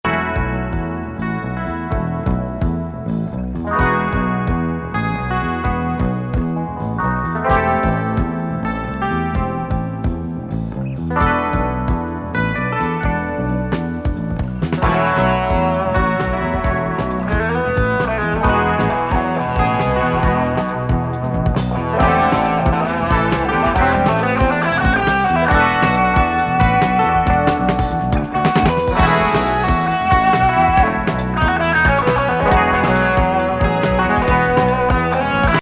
仏ヴァイオリン奏者78年作！COSMICスペース・グルーヴィーフュージョン！